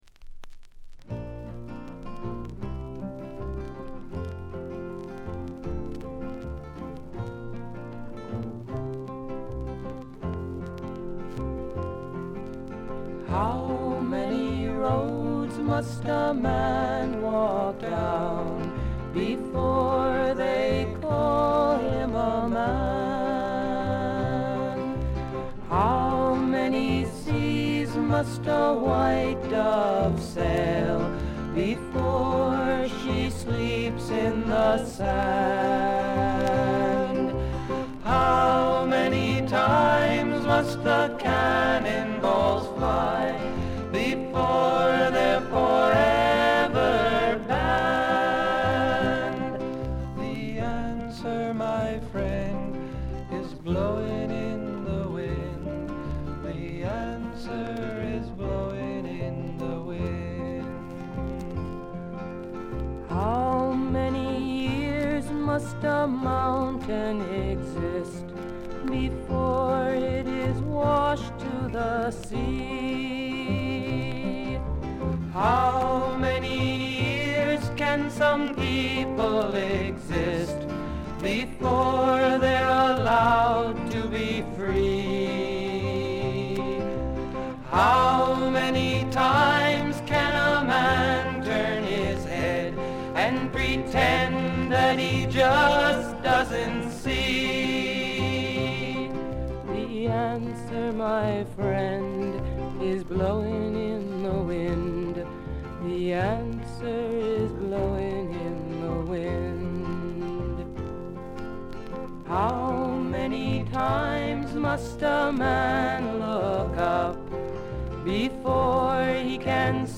バックグラウンドノイズやチリプチ、プツ音等多め大きめ。
試聴曲は現品からの取り込み音源です。